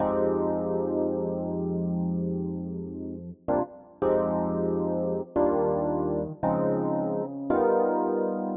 06 ElPiano PT4.wav